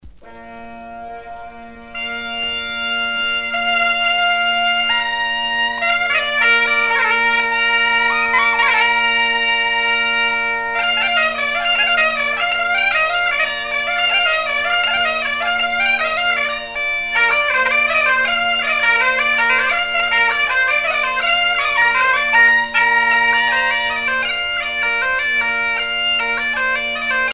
danse : gavotte bretonne